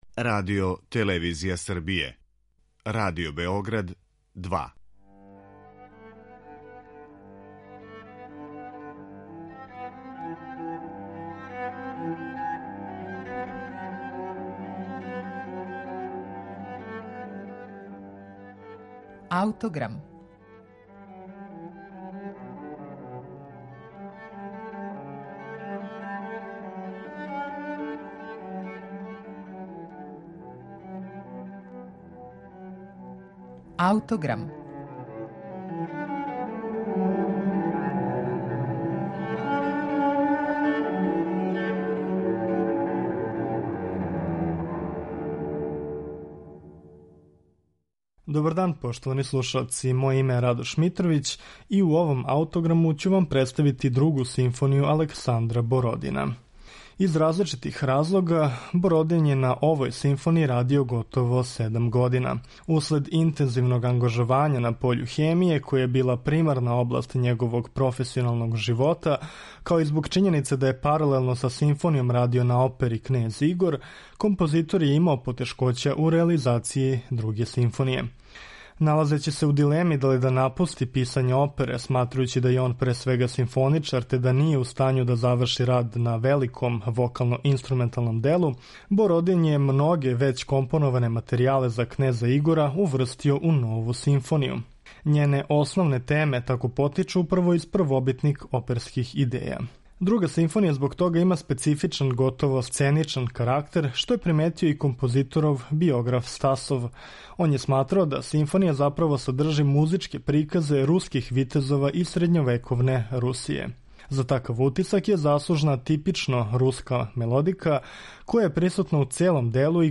медитативне и духовне композиције
најобимније хорско дело